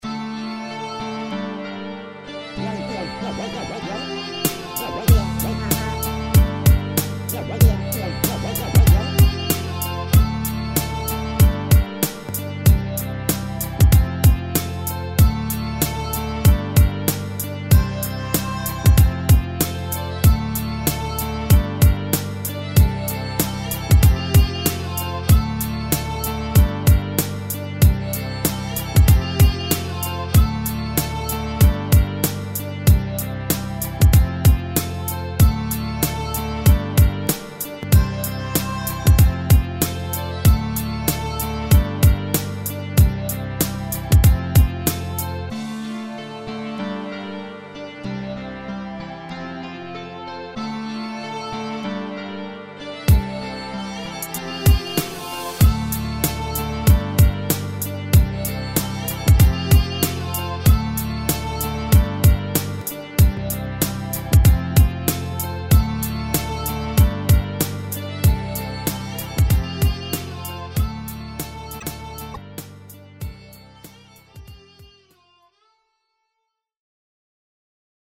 Качаем и ценим мой новый минус (демо версия). Только что свёл.
viol-piano.mp3